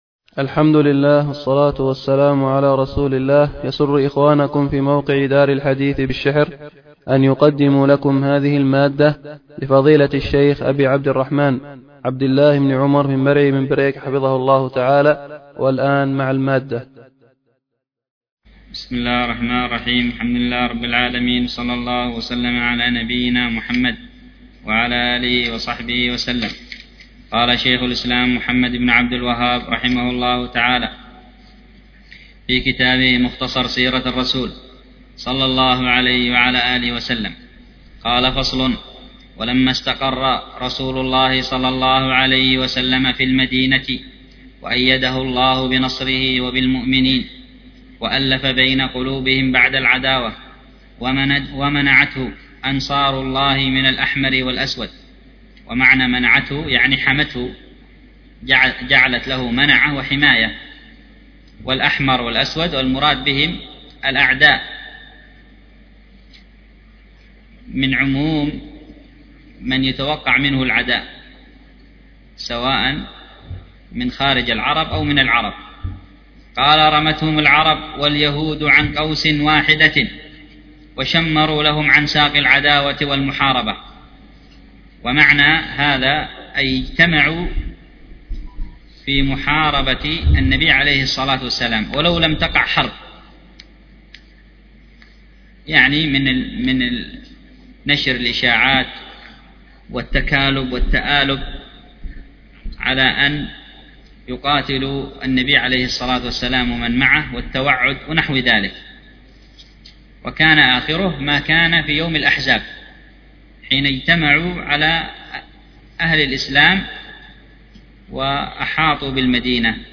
الدرس في شرح مختصر السيرة 26، الدرس السادس والعشرون : وفيه : ( ثم أنشد ورقة ...